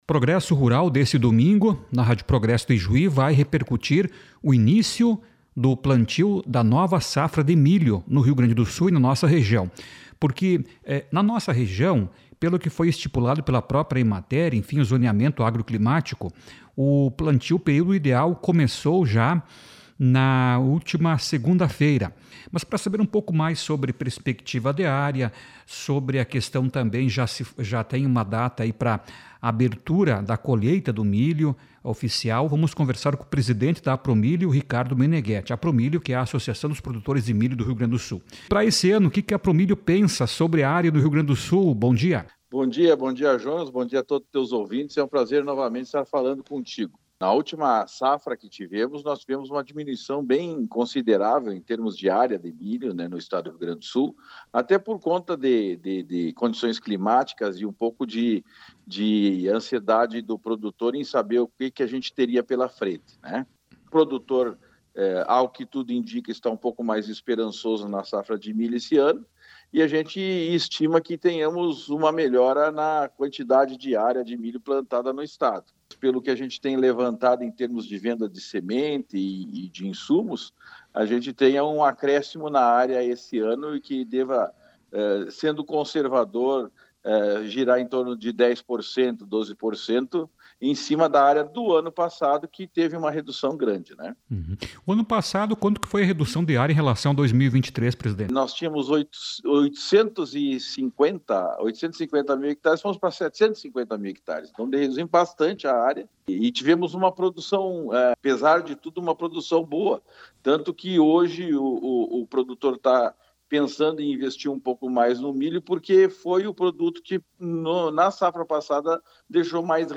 numa entrevista